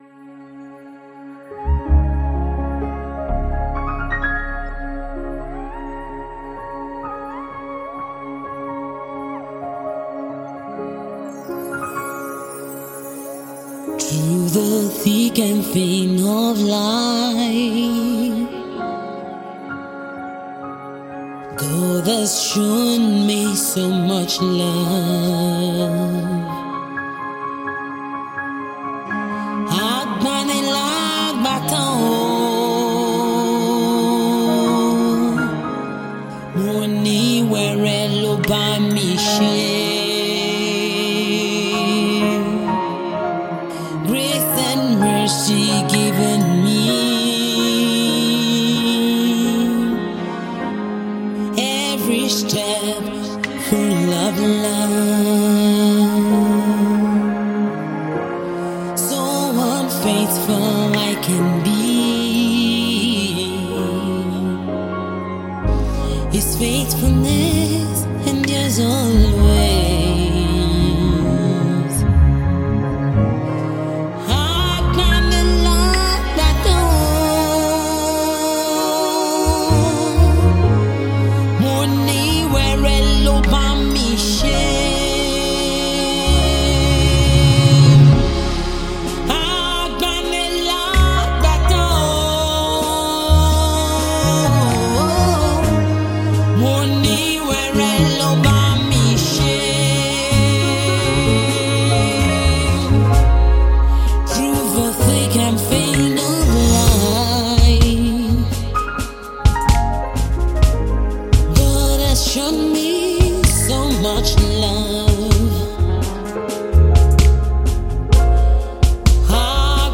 Gospel music
worship song